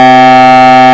These are mp3 files generated by Audacity freeware ( available here ), based on Keely's drawings of his acoustic model of atoms and molecules.
The tones are in the A = 432 Hz Equal Tempered Scale :
C4-E5-G5
ET5=C4E5G5.mp3